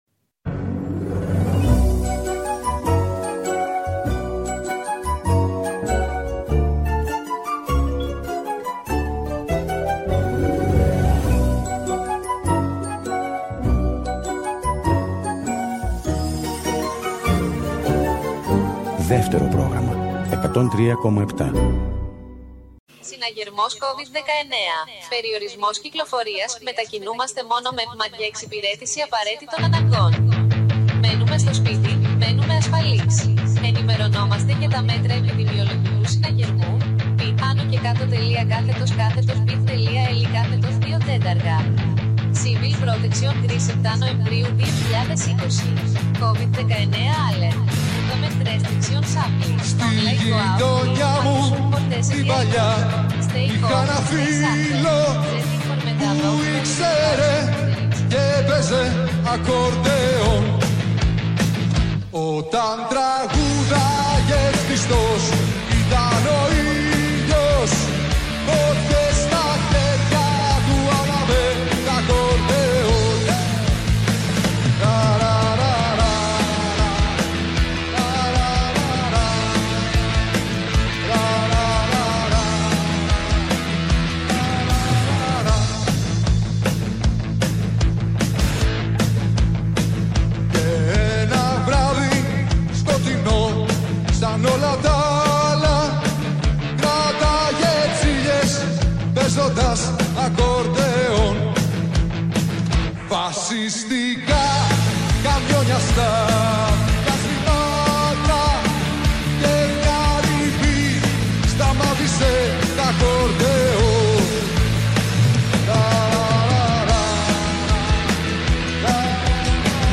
Σε μια …ηλεκτρισμένη εκπομπή – διάλογο με μερικά από τα πιο ωραία τραγούδια που γράφτηκαν ποτέ στο ελληνικό ρεπερτόριο και ήταν δικά του.